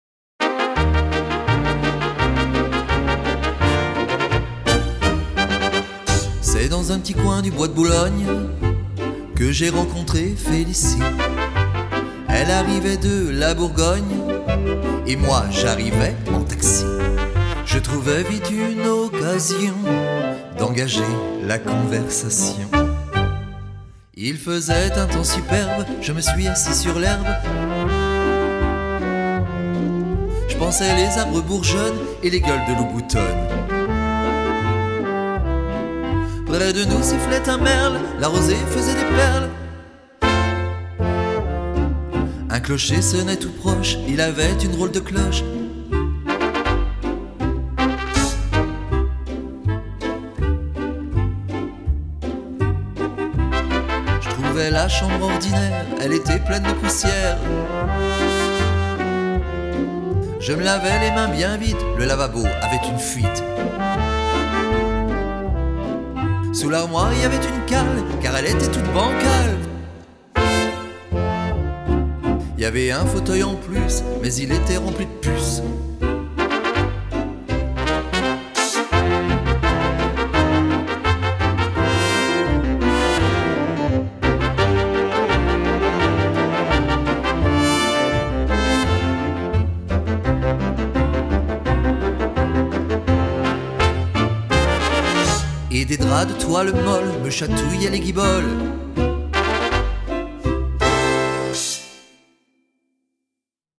La version chantée avec les trous (Facile)